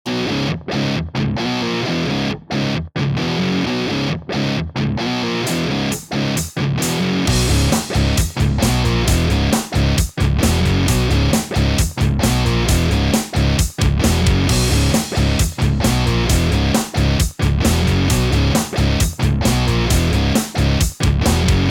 Электрогитара "Ibanez RG 350 EX Black" .
Несколько фрагментов с этой гитарой: